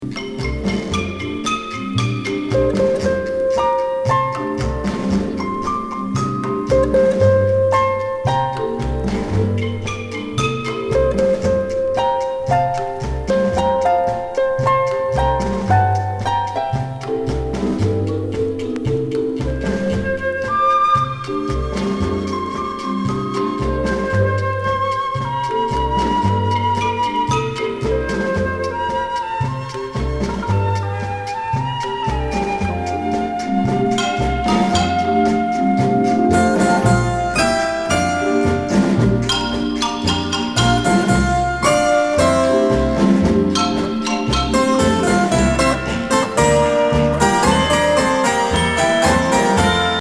なイージーリスニング・アルバム！